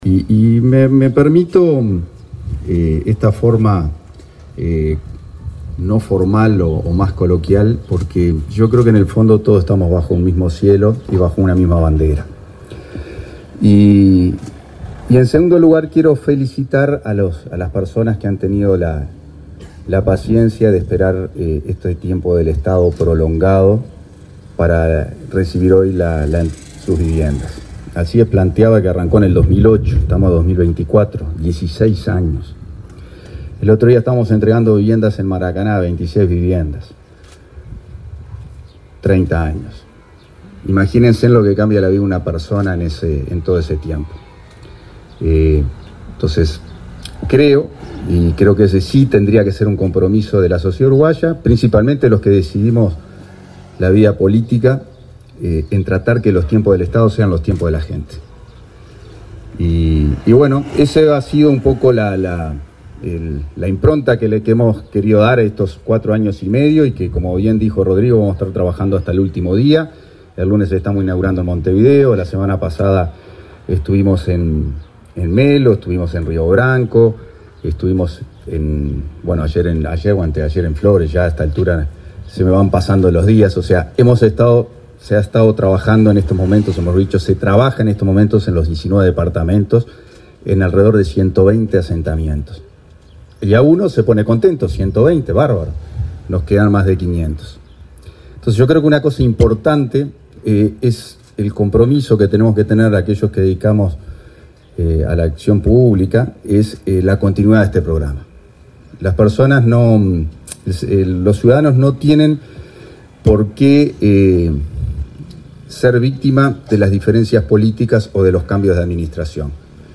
Palabras del subsecretario de Vivienda, Tabaré Hackembruch
El subsecretario de Vivienda, Tabaré Hackembruch, se expresó, durante el acto de inauguración de siete viviendas del Plan Avanzar en Pando Norte,